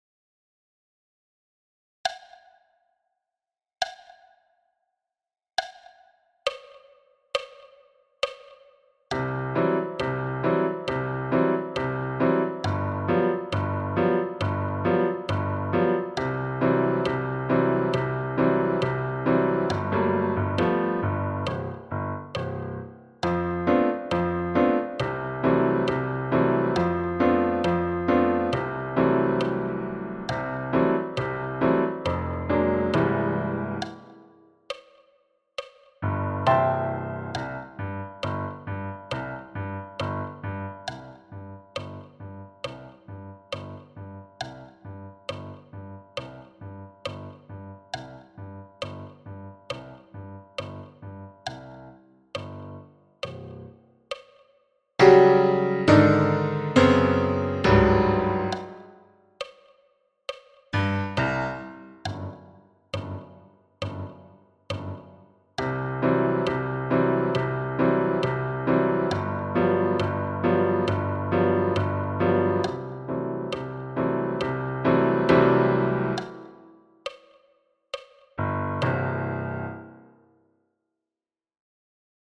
Le-cow-boy-Arthur-68-bpm
Le-cow-boy-Arthur-68-bpm.mp3